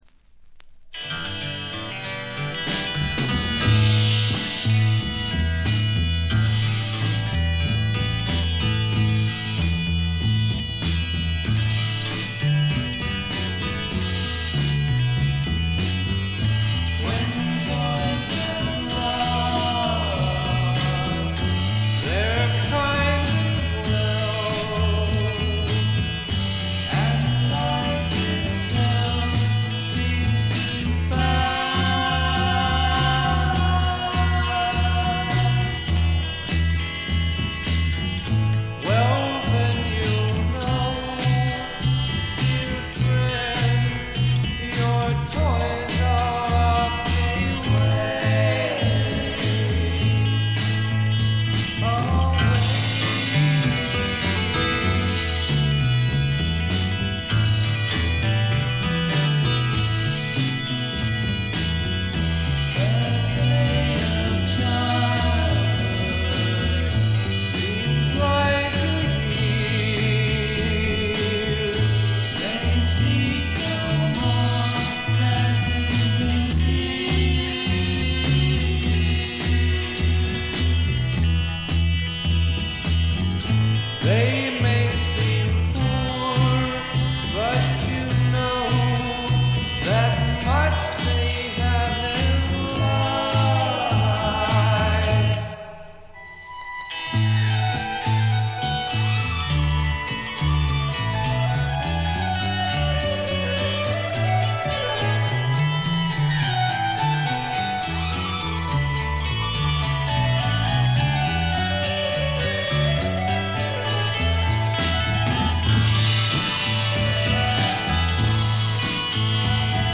静音部での軽微なバックグラウンドノイズ程度。
素晴らしいサイケデリック名盤です。
試聴曲は現品からの取り込み音源です。
Recorded At - Sound City Inc, Recording Studios